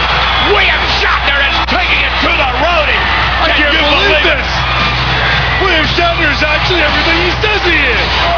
…and climaxed by sending Road Dogg over the top rope and to the floor, all while commentators Vinnie Mac and Shawn Michaels